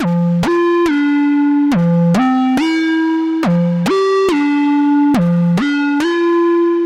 关键。F 纳波利坦小调
Tag: 140 bpm Chill Out Loops Synth Loops 1.15 MB wav Key : F